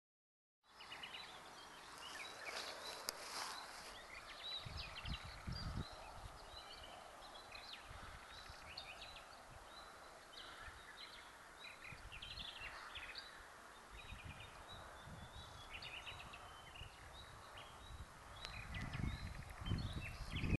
As well as birdsong in Rome vineyard.